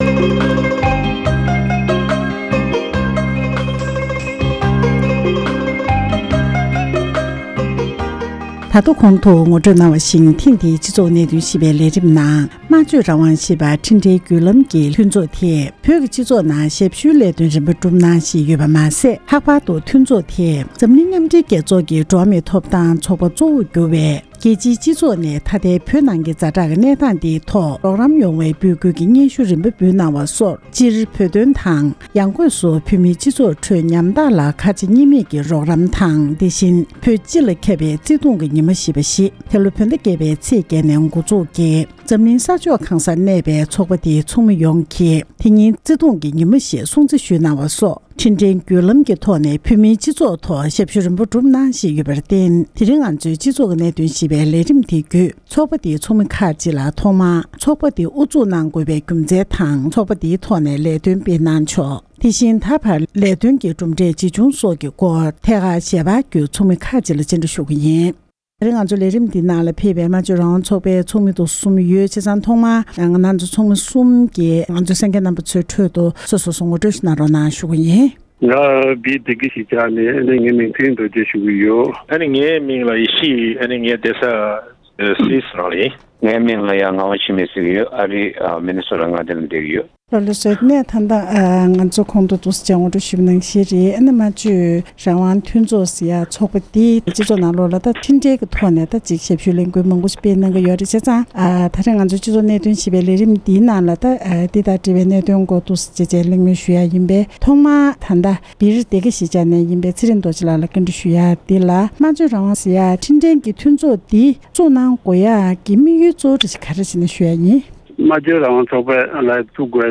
འབྲེལ་ཡོད་མི་སྣར་ཐད་ཀར་ཞལ་པར་བརྒྱུད་གནས་འདྲི་ཞུས་པ་ཞིག་གསན་རོགས་གནང་།།